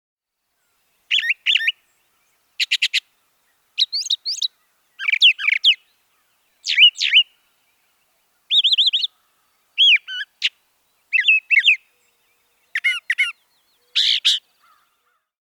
This brown, streaky songbird with a bright yellow eye lives much of its life hidden in tangles.
Enjoy this bird's amazingly diverse vocal repertoire, rivaling its mockingbird and catbird relatives.